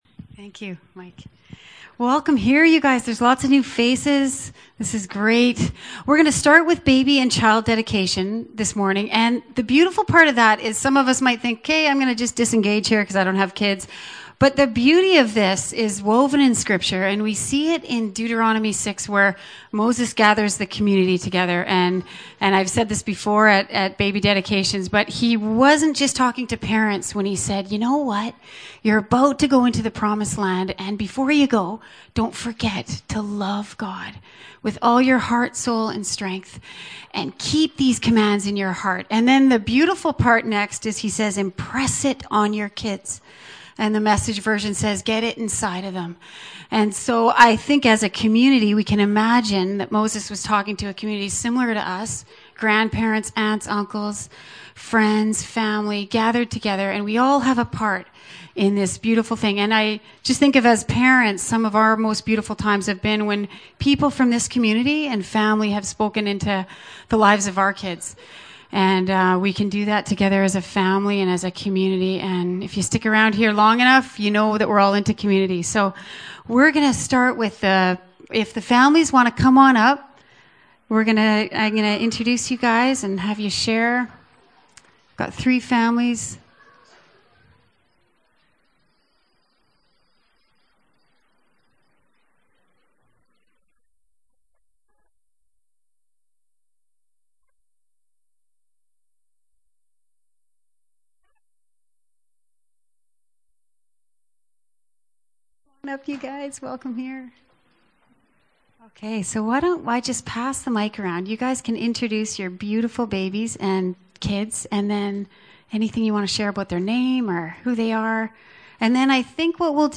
This morning we hear, see and witness some of the fruits of our kid’s “Gifts4God” exploration of the past few months! The recording included dances and videos and begins with Child and Baby dedications.